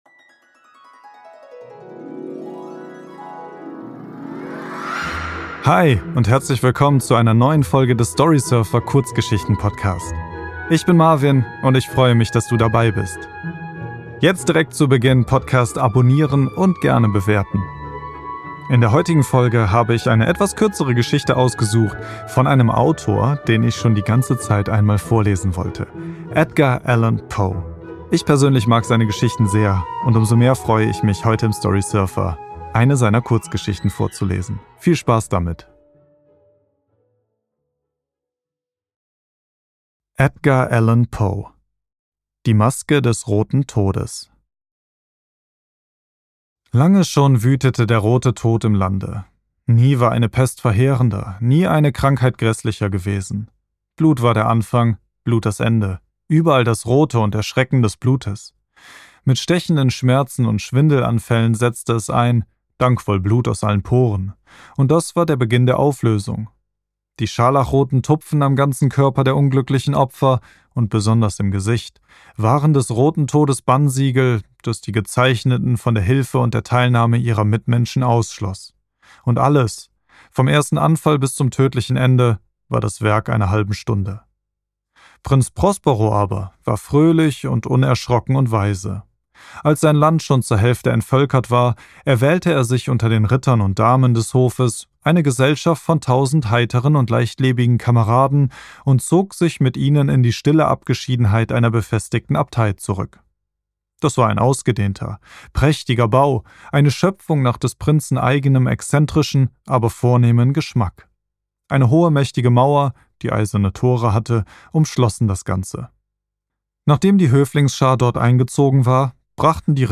Edgar Allen Poe - Die Maske des Roten Todes | Mystery und Horror | Storysurfer Podcast ~ Storysurfer - Der Kurzgeschichten Hörbuch Podcast